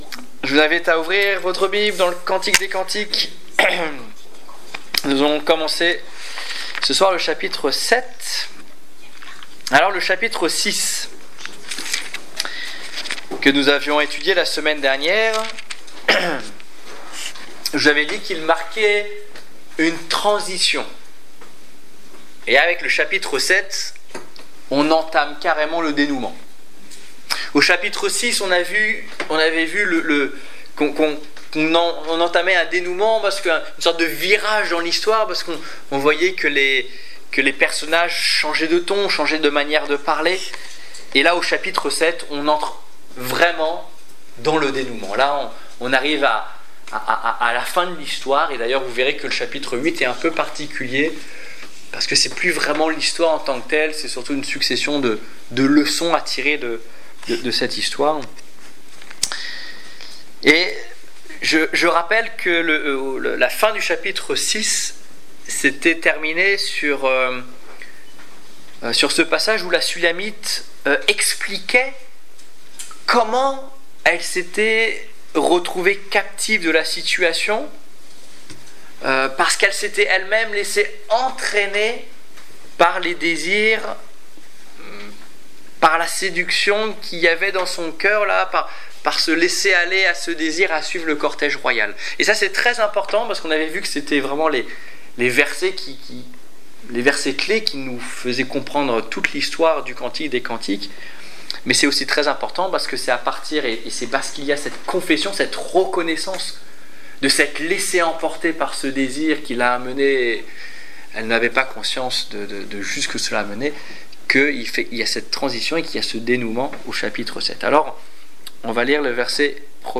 Étude biblique du 23 septembre 2015